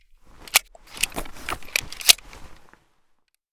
pm_reload.ogg